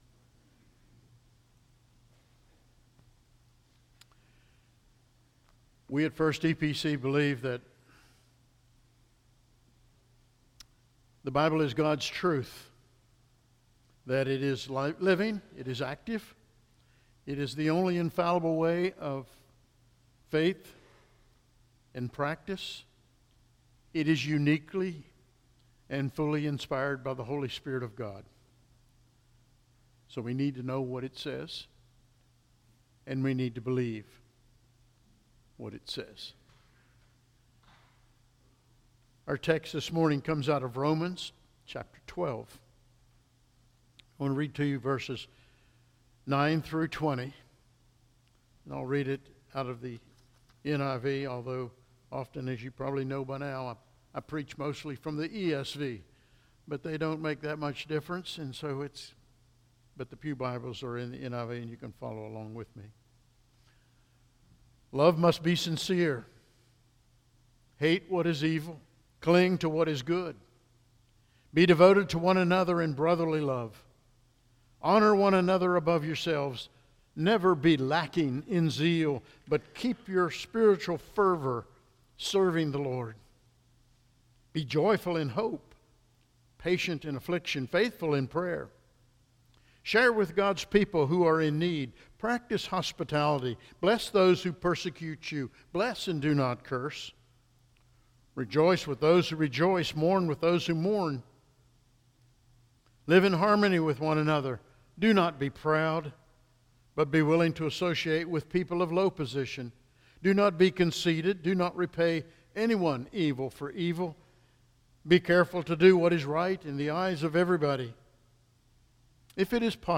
The 16th of February saw us host our Sunday morning service from the church building, with a livestream available via Facebook.